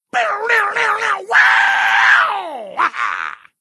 mouth-guitar_09
Category: Games   Right: Personal